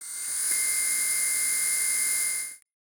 Elevator moving.ogg